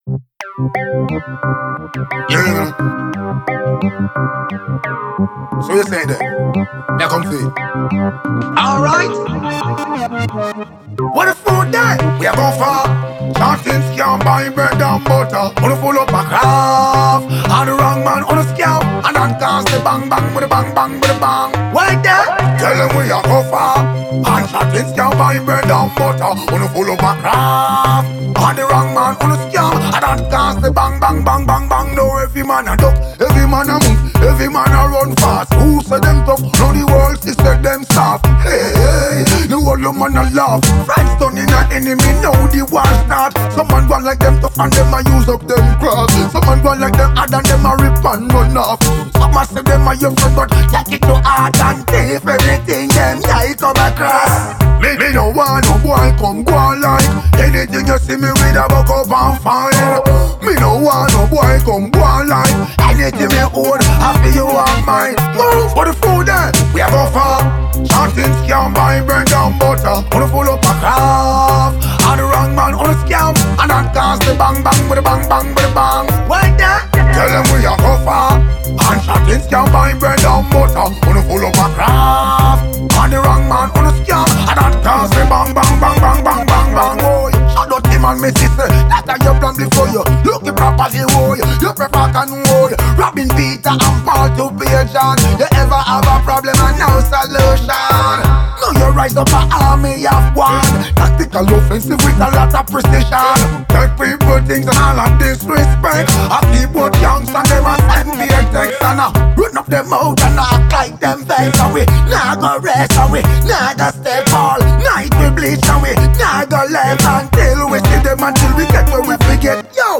New one from Raggae-Dancehall Star